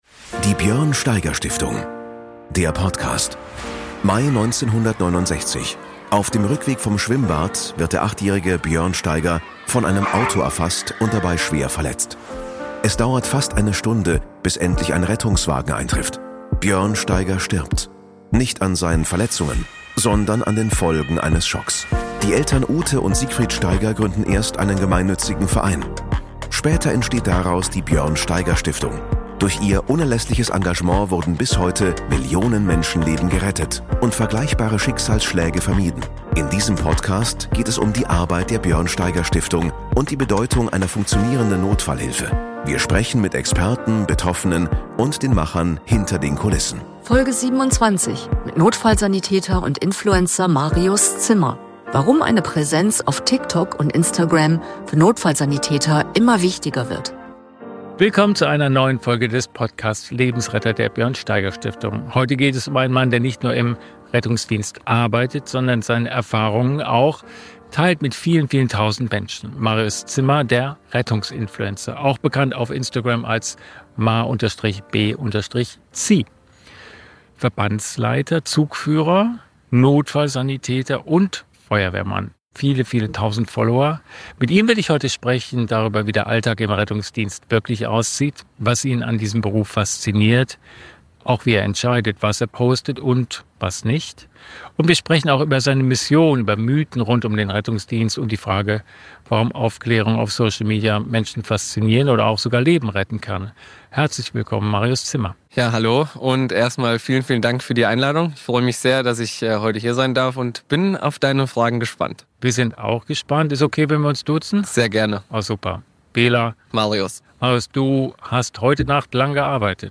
Zudem spricht er über Mythen, die sich um den Beruf ranken, und darüber, welche Rolle Social Media in der Zukunft der Notfallversorgung spielen kann. Themen dieser Folge: Die wachsende Bedeutung von Social Media für den Rettungsdienst Einblicke in den Arbeitsalltag eines Notfallsanitäters Umgang mit belastenden Einsätzen und deren Verarbeitung Herausforderungen und Kritik im Online-Bereich Fehlannahmen über den Rettungsdienst und die Realität im Einsatz Wir freuen uns über Feedback zur Folge und Anregungen für zukünftige Themen.